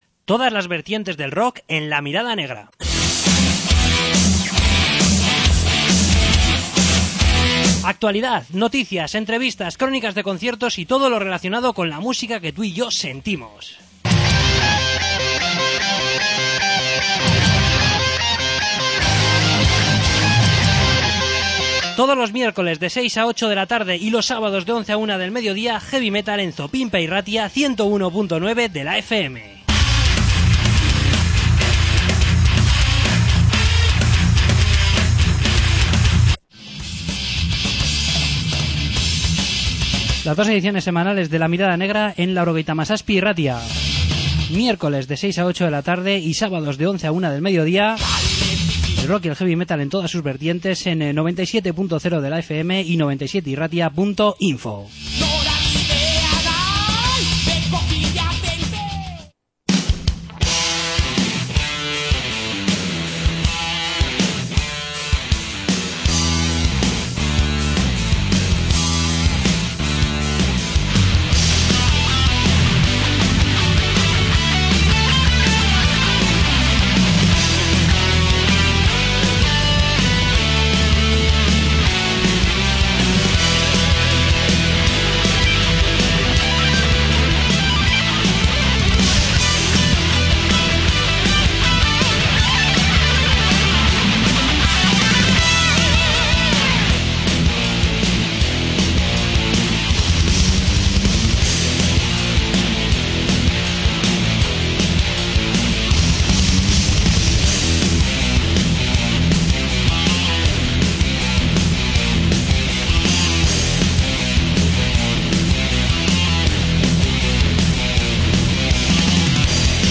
Entrevista con Monasthyr